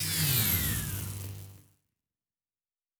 Weapon 08 Stop (Laser).wav